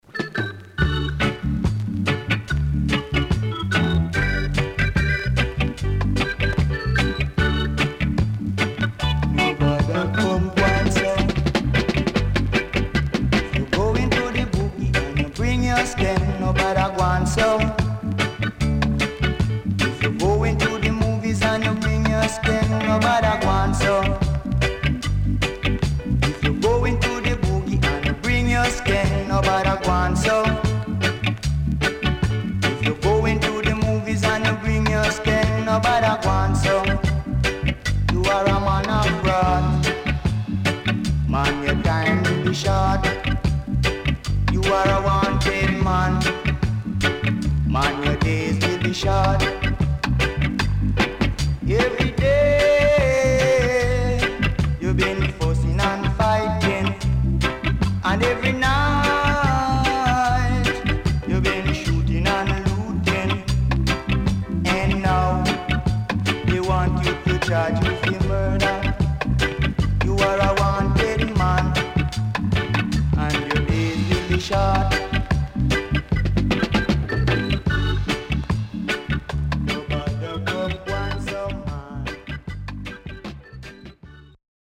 Nice Vocal & Dubwise